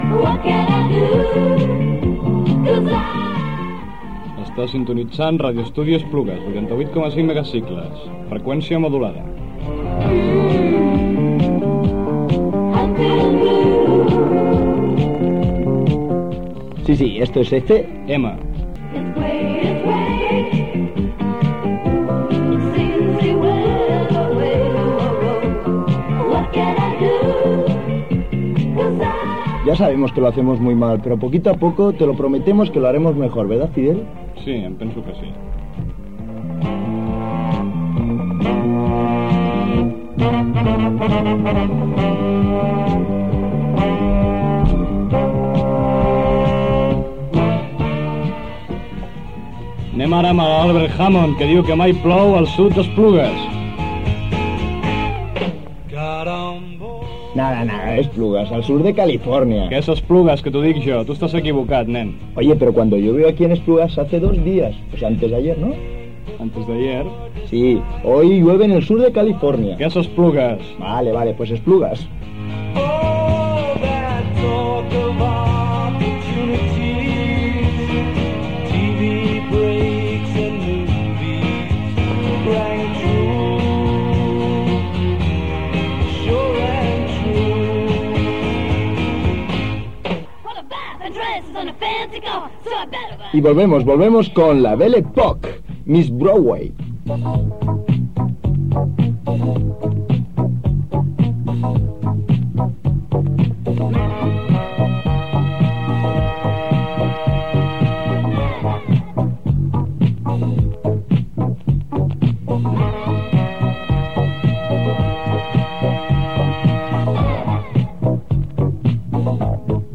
Temes musicals
Musical
FM